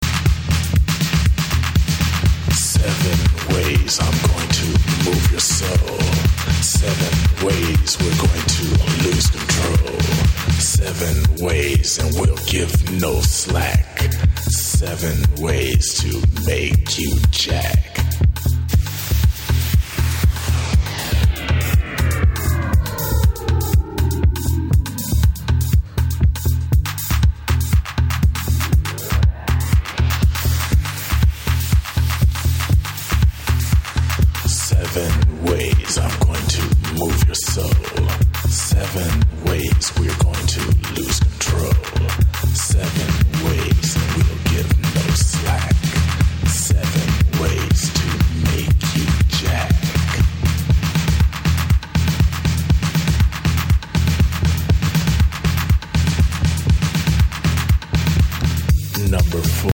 ホーム > HOUSE/BROKEN BEAT > V.A